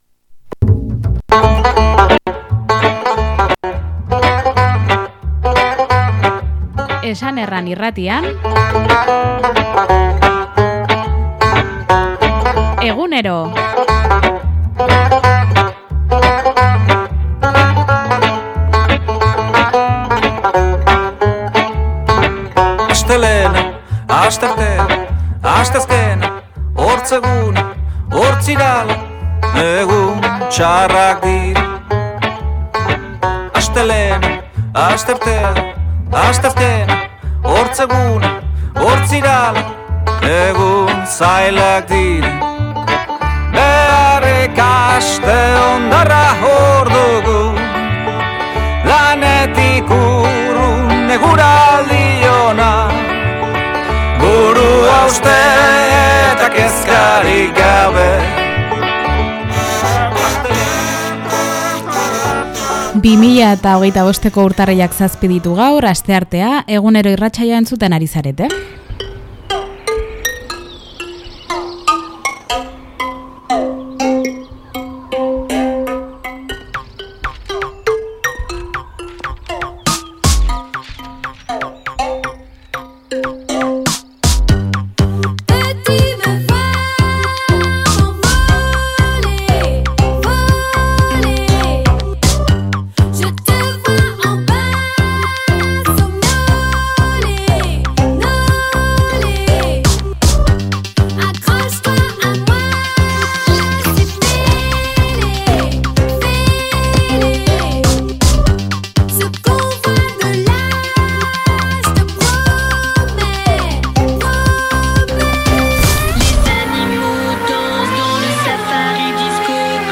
Urte berriko lehendabiziko irratsaioa izan dugu gaurkoa! ZETAK taldearen ‘Mitoaroa’ ikuskizunean parte hartu dute Lanzko hogei bat lagunek, ihauterietako pertsonaiak eraman baitituzte Nafarroa Arenara.